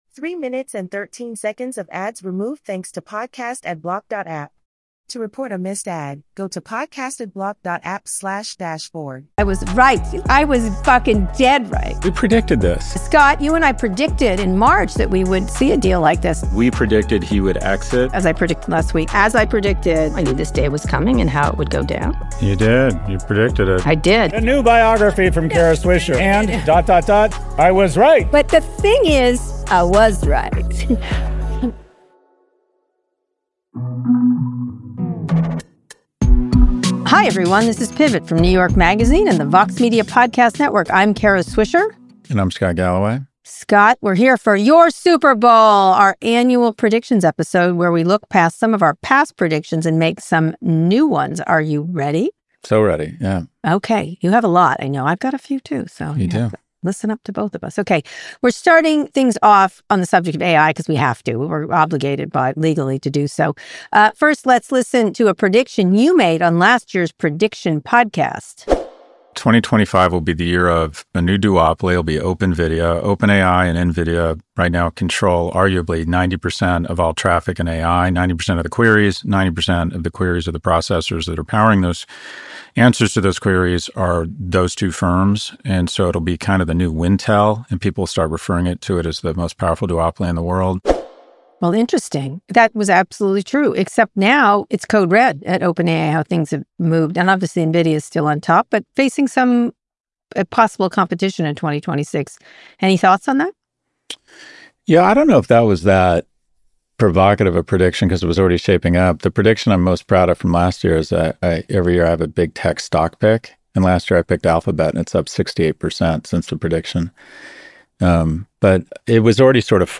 The resulting MP3 file indeed contains no ads, as well as an inserted message saying how many ads were cut out.